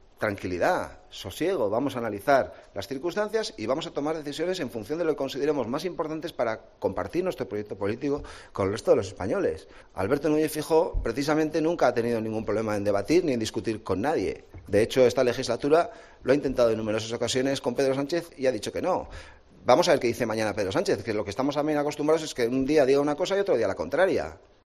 El portavoz nacional del PP, Borja Sémper
Según ha asegurado Semper en rueda de prensa, Pedro Sánchez "está más cómodo en un plató" de televisión que en la calle y ha dejado claro que el PP no se va a dejar "marcar la agenda por la ansiedad de Pedro Sánchez".